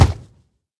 assets / minecraft / sounds / mob / hoglin / step2.ogg
step2.ogg